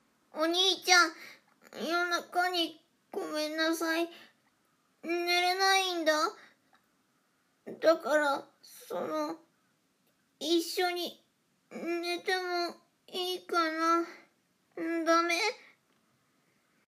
サンプルボイス ショタっ子 【少年】